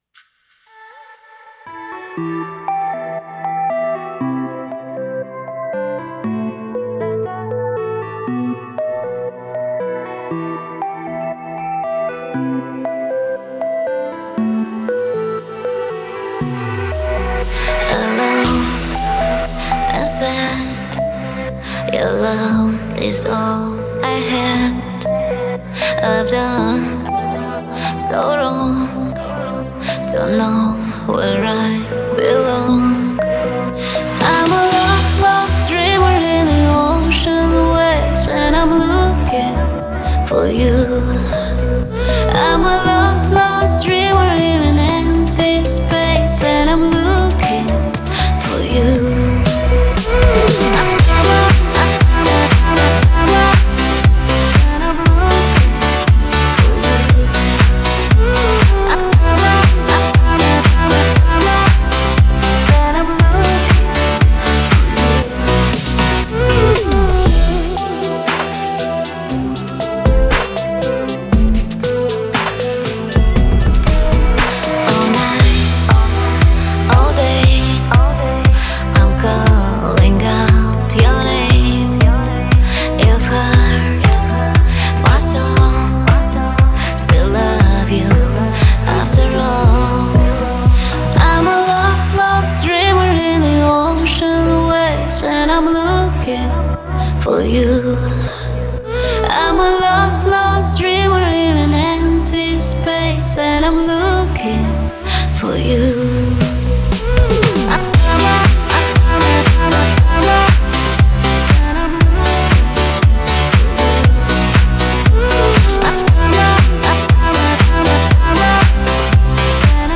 Ее мелодия легко запоминается